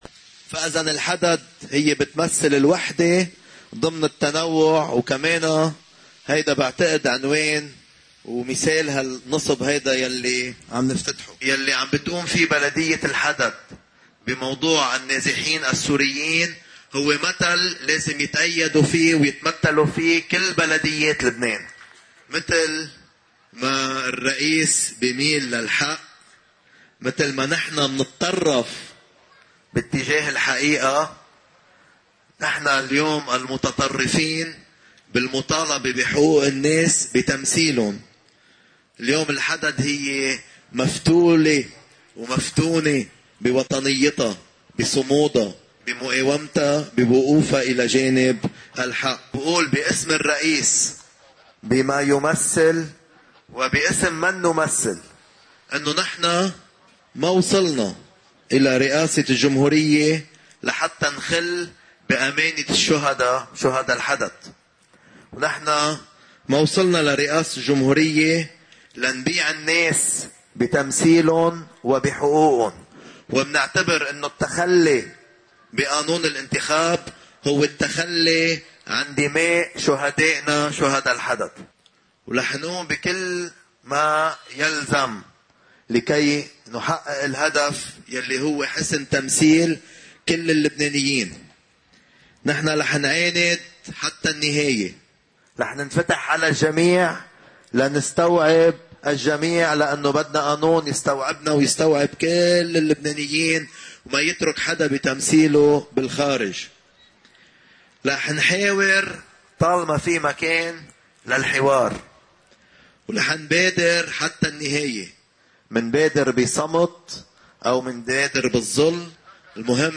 مقتطف من حديث رئيس التيار الوطني الحر، في حفل تدشين شارح الرئيس ميشال عون في بلدة الحدت وتكريس سيدة الحدت: